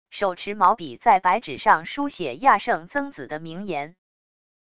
手持毛笔在白纸上书写亚圣曾子的名言wav音频